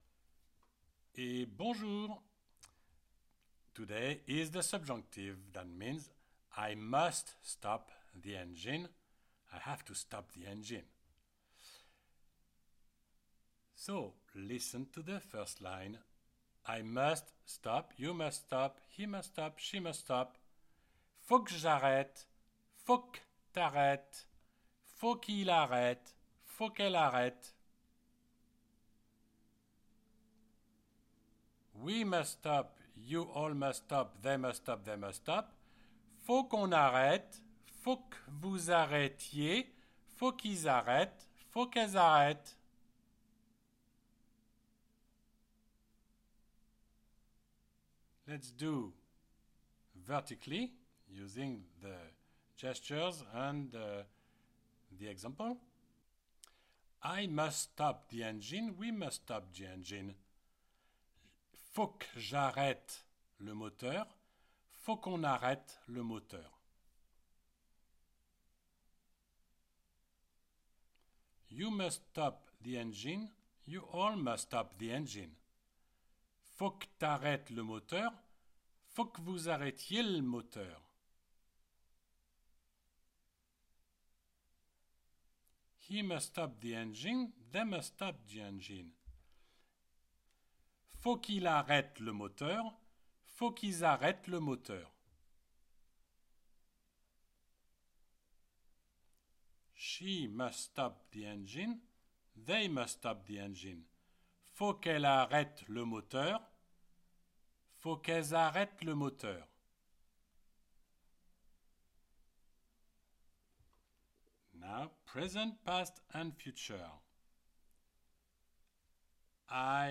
THAT IS ALSO WHY I SIMPLY ASK YOU TO IMITATE ME OVER AND OVER USING A LANGUAGE THAT IS CONTRACTED SO THAT YOU WILL FEEL EMPOWERED WHEN THE TIME COMES FOR YOU TO COMMUNICATE WITH THE FRENCH.